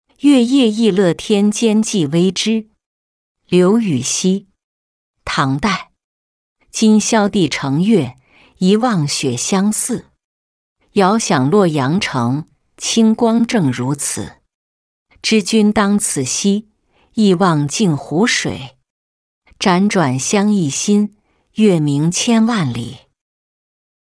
月夜忆乐天兼寄微之-音频朗读